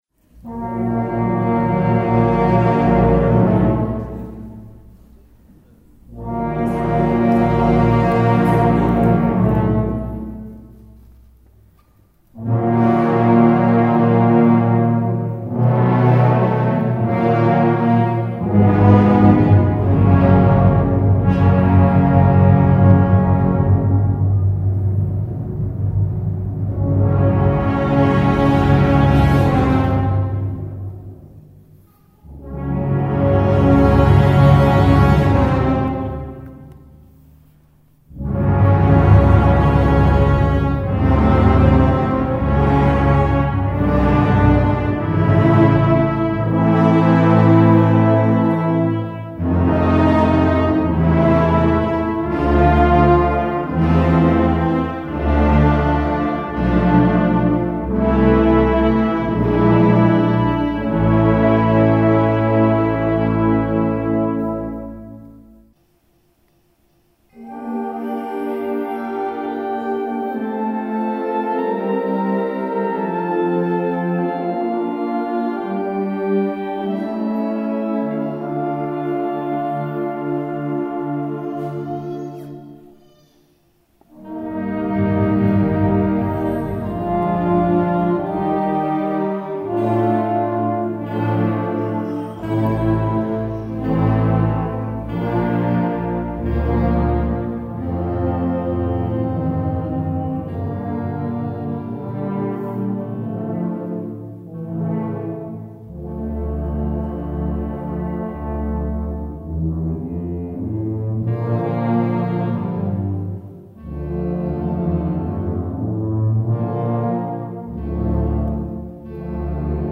enregistré à Fraire pendant le concert de Sainte-Cécile 2009 par l'harmonie de Fraire